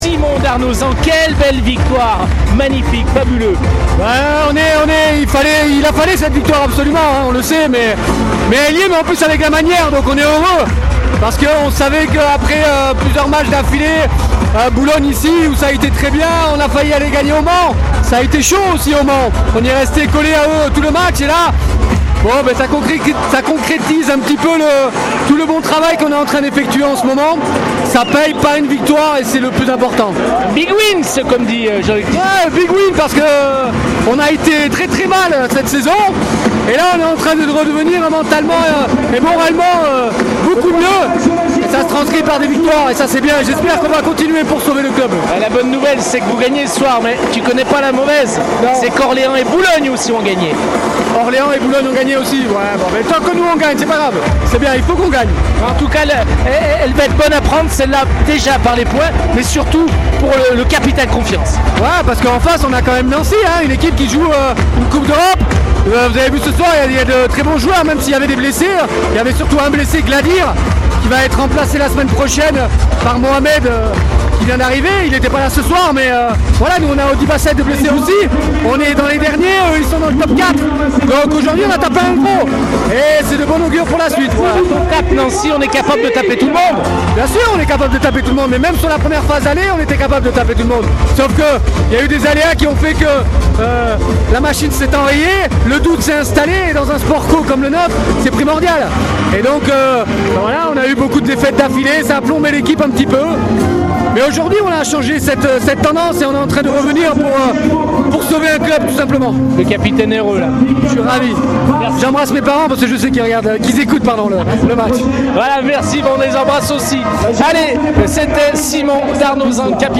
On écoute les réactions d’après-match au micro Radio Scoop